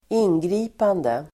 Uttal: [²'in:gri:pande]